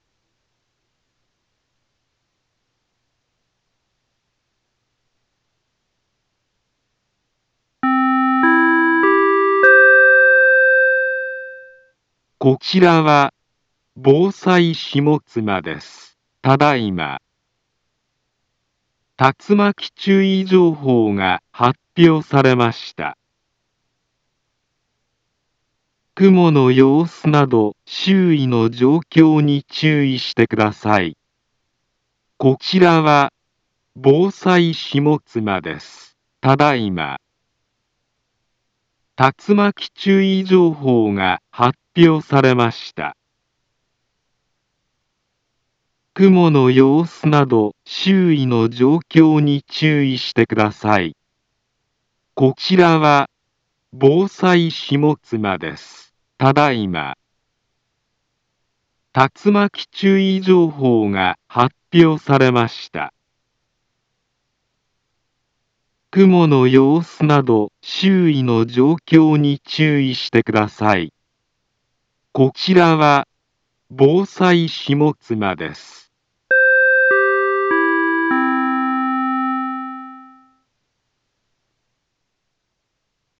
Back Home Ｊアラート情報 音声放送 再生 災害情報 カテゴリ：J-ALERT 登録日時：2023-04-16 15:45:17 インフォメーション：茨城県南部は、竜巻などの激しい突風が発生しやすい気象状況になっています。